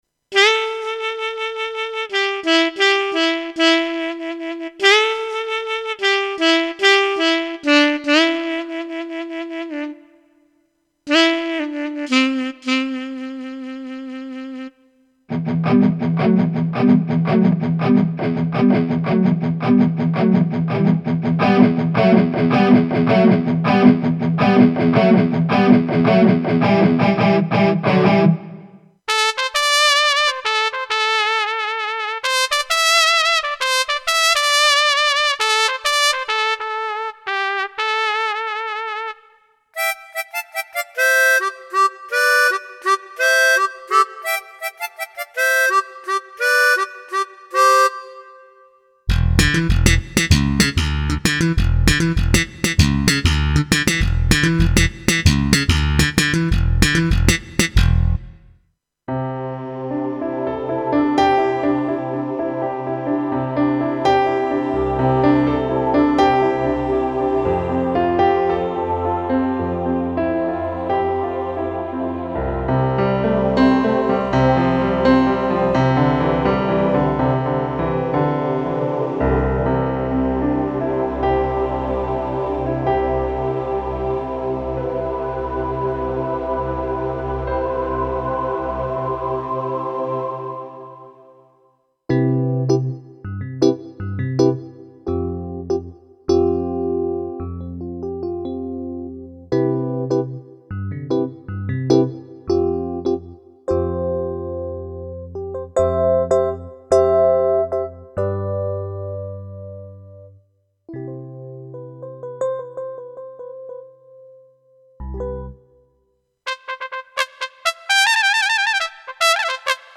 Прилагаю так же звучание некоторых патчей и фабричное демо.
patch_demo.mp3